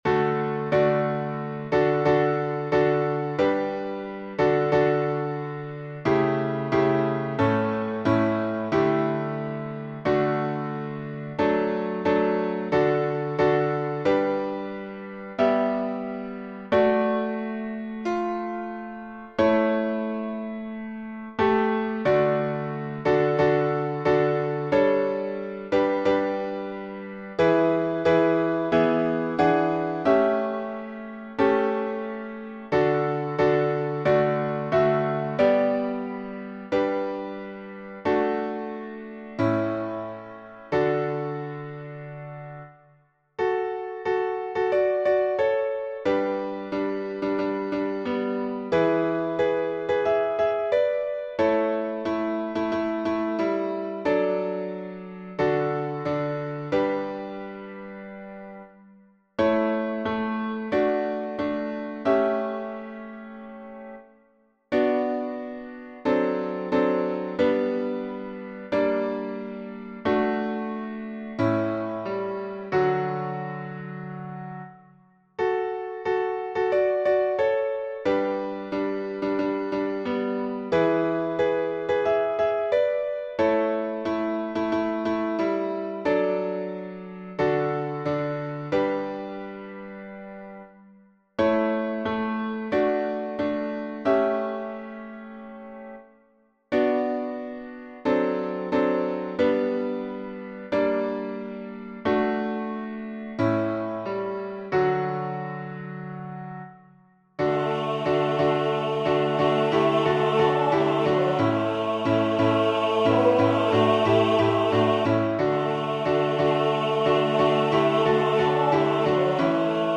How Excellent Is Thy Loving Kindness — Slower.
SATB arrangement attributed to J.
Key signature: D major (2 sharps) Time signature: 2/2 and 12/8 Meter: Irregular
How_Excellent_Is_Thy_Loving_Kindness_slow.mp3